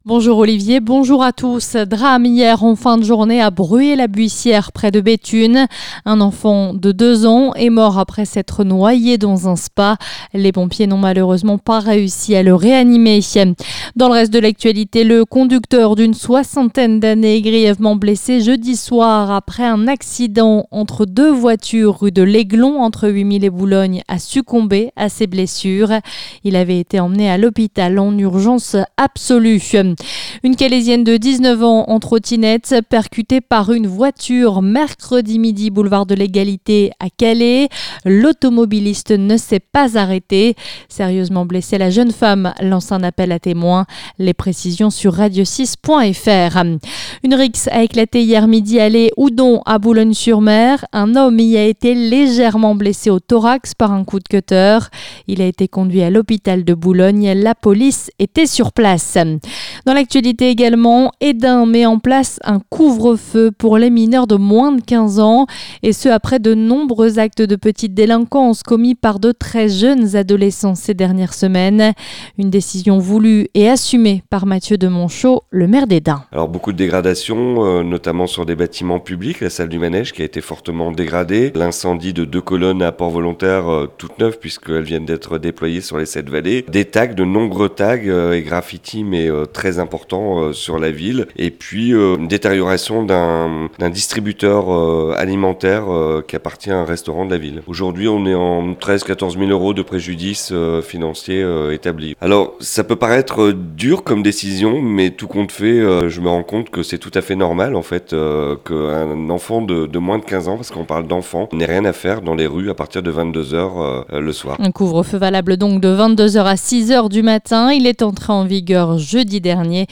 Le journal du samedi 3 août